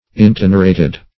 Intenerate \In*ten"er*ate\, v. t. [imp. & p. p. Intenerated;